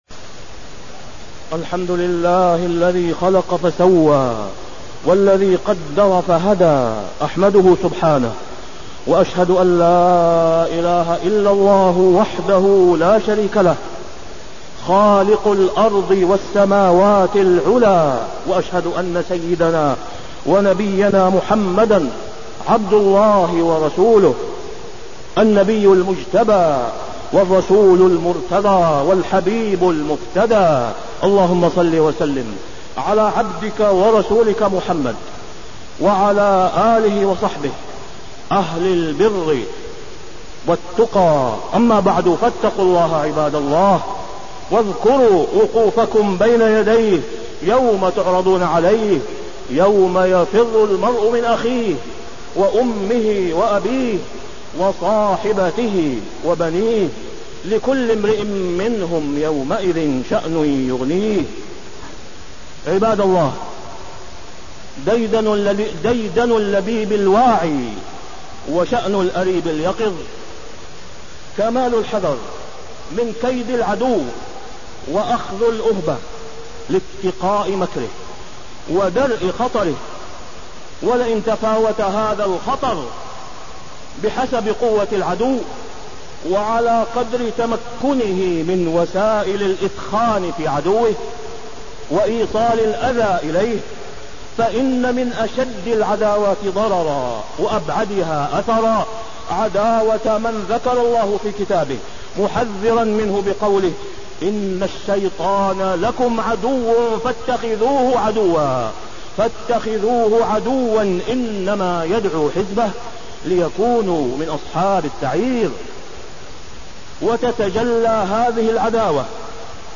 تاريخ النشر ٦ ربيع الثاني ١٤٣٢ هـ المكان: المسجد الحرام الشيخ: فضيلة الشيخ د. أسامة بن عبدالله خياط فضيلة الشيخ د. أسامة بن عبدالله خياط الفتنة ودور الشيطان في إشعالها